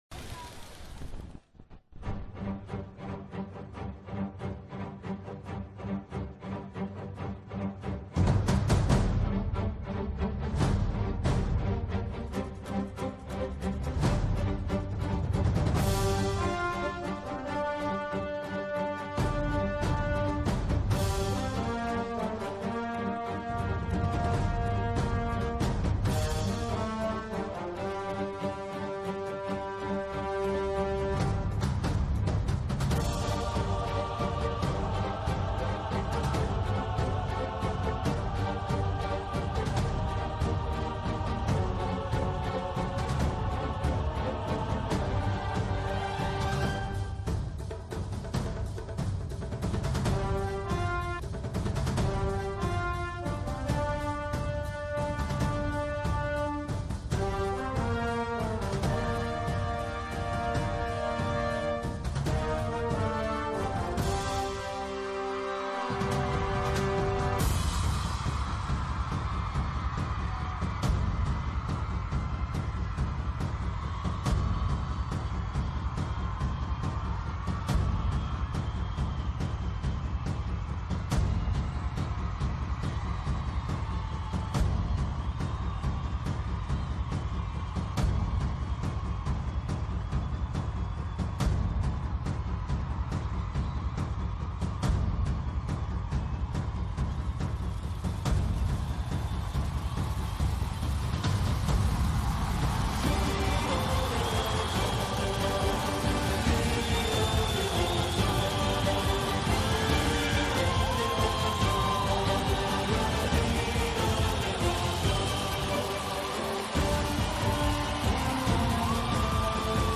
فیلم – استقبال بیش از ۱۰۰ هزار نفر از خانم مریم رجوی در پاریس – ۱۹ تیر ۱۳۹۵